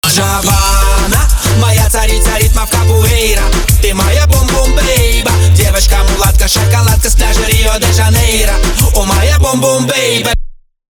• Качество: 320, Stereo
ритмичные
веселые
заводные
dancehall